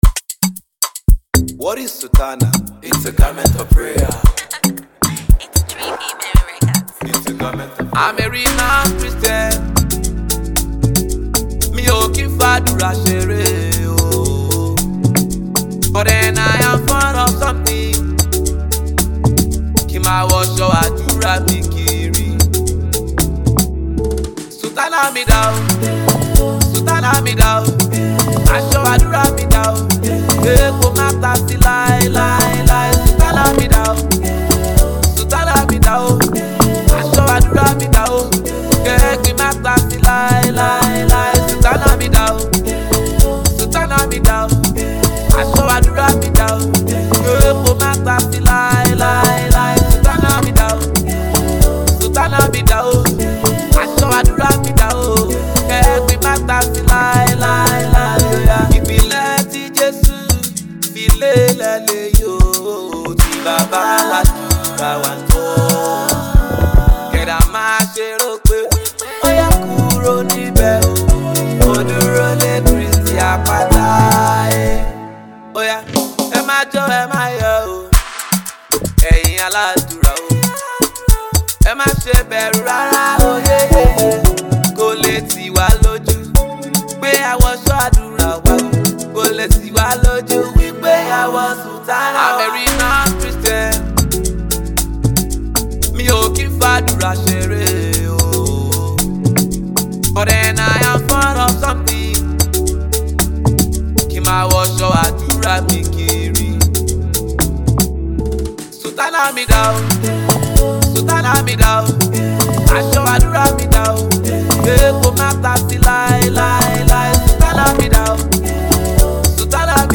a rising star in Nigeria’s Gospel realm.
uplifting spiritual experience